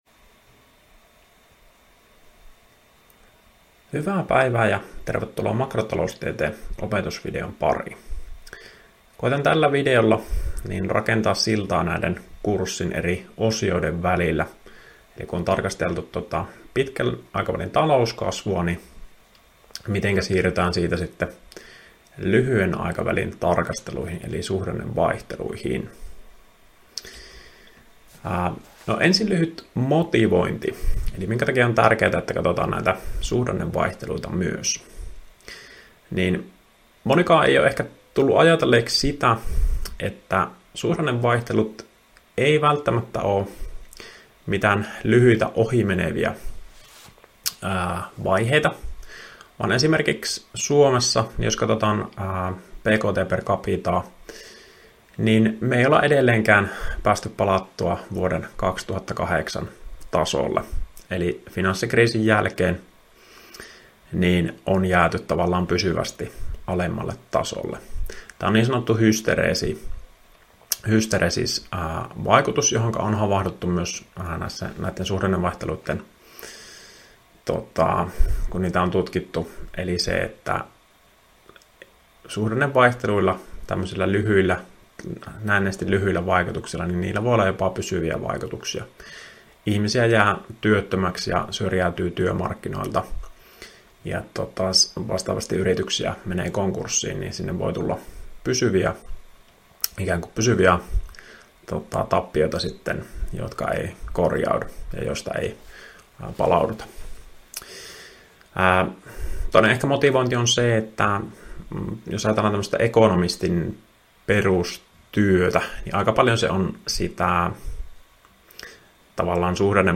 Opintojakson "Makrotaloustiede I" suhdanneosion 1. opetusvideo.